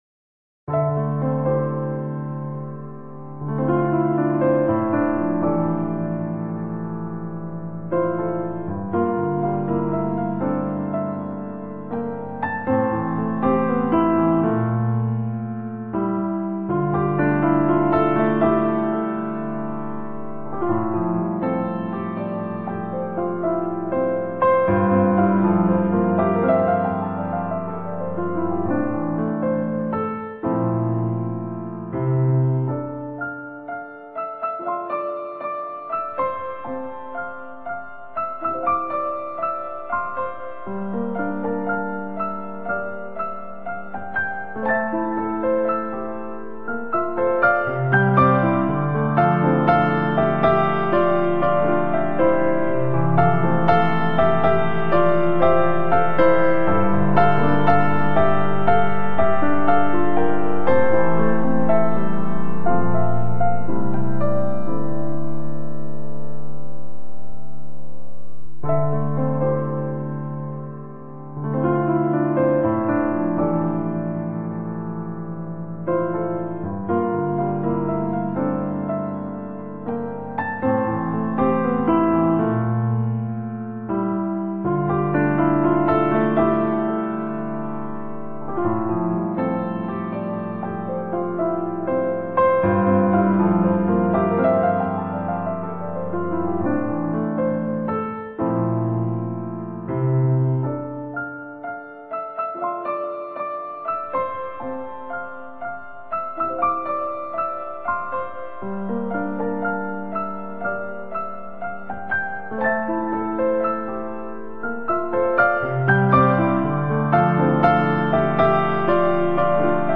applause-1.mp3